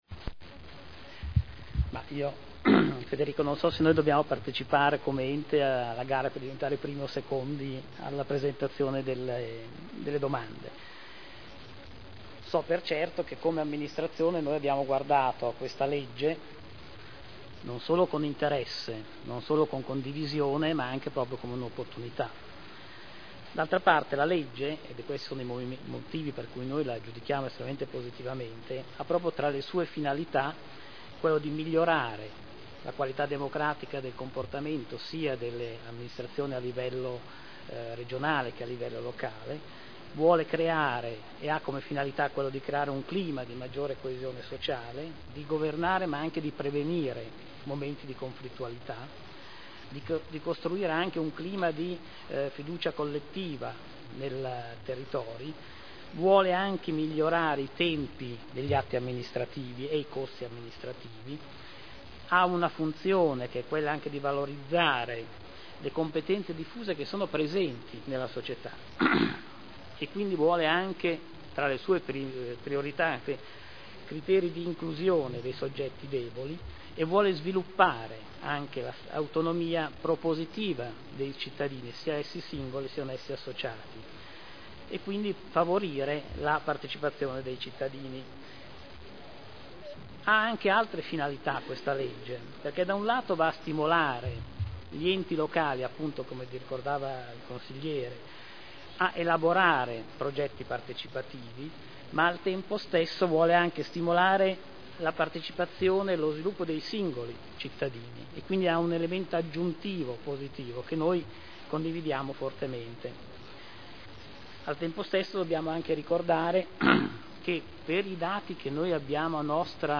Alvaro Colombo — Sito Audio Consiglio Comunale